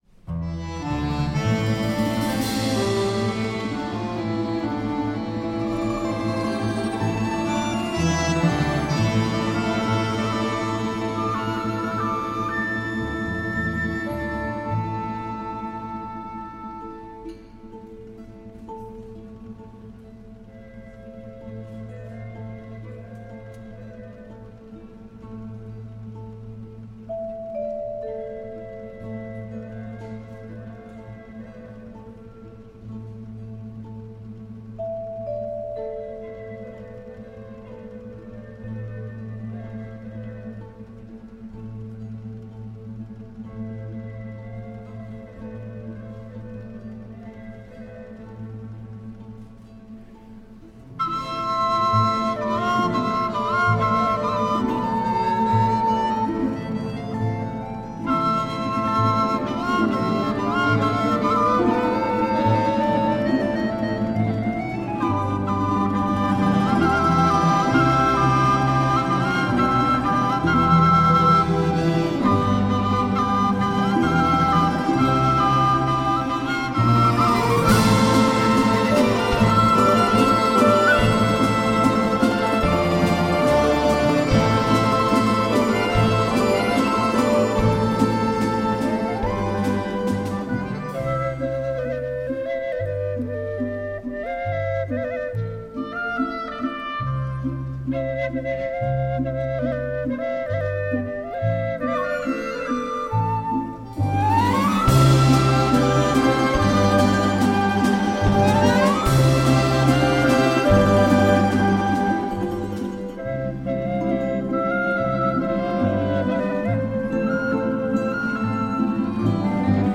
Отличается способностью исполнения двухголосия.